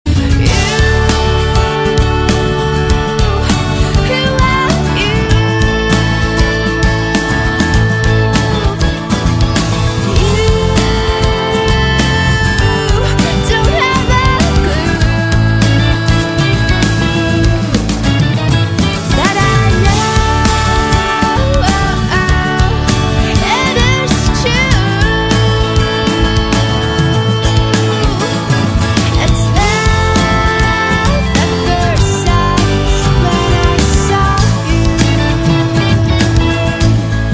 M4R铃声, MP3铃声, 欧美歌曲 98 首发日期：2018-05-15 08:12 星期二